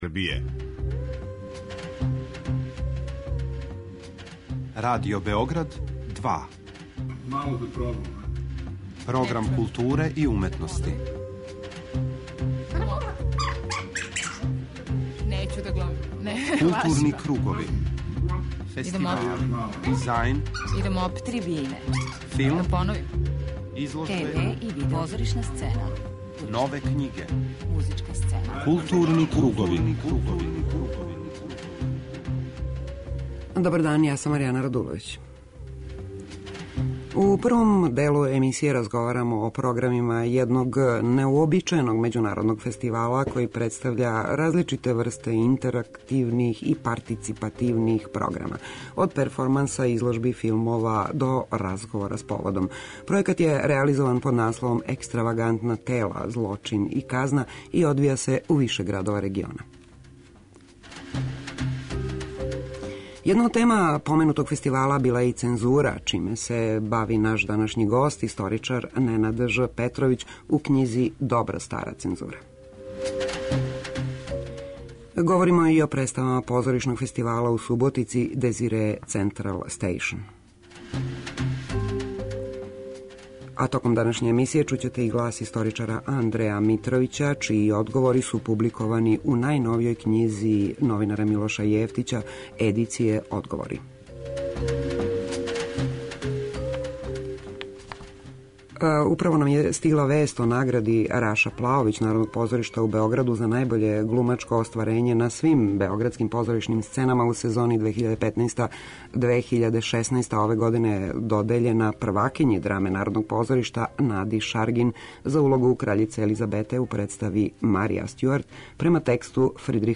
У тематском блоку Арс сонора, приказаћемо концерт ЛП дуа који је одржан у оквиру фестивала Take Over, као и завршни концерт Филхадемије.
преузми : 40.15 MB Културни кругови Autor: Група аутора Централна културно-уметничка емисија Радио Београда 2.